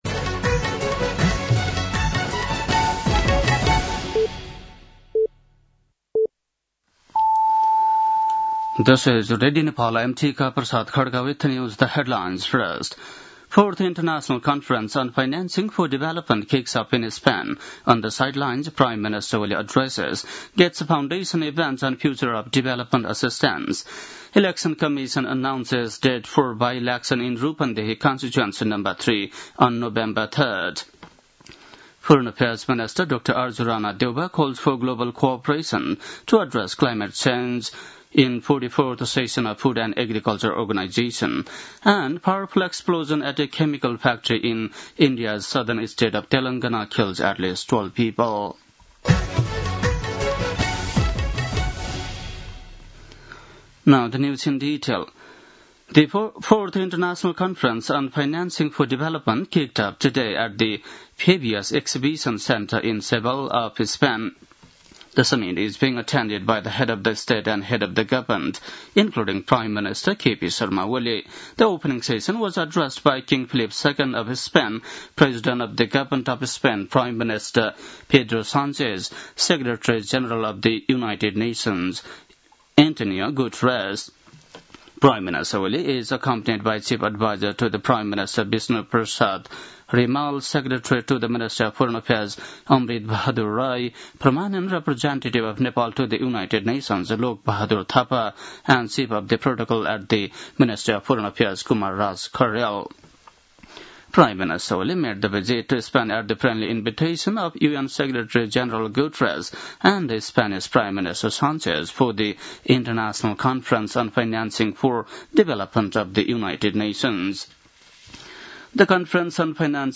बेलुकी ८ बजेको अङ्ग्रेजी समाचार : १६ असार , २०८२
8-pm-english-news-3-16.mp3